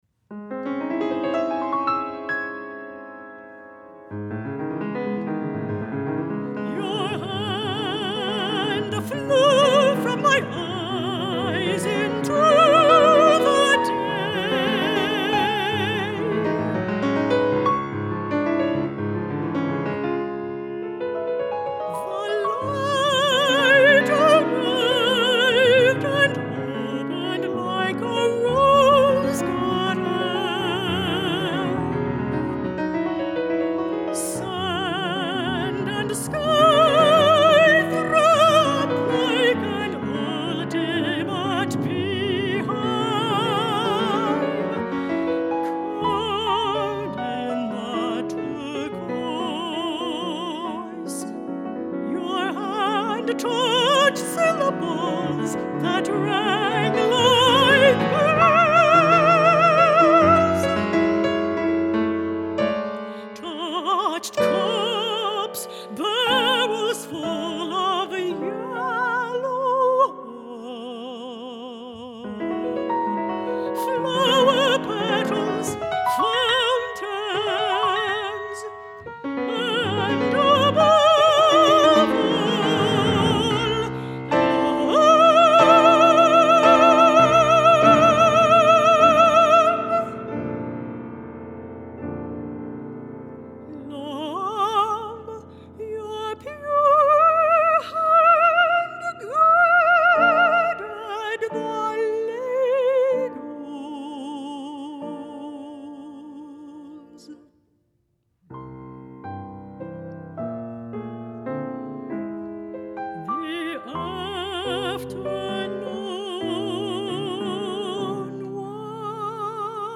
Soprano or Mezzo-Soprano & Piano (14′)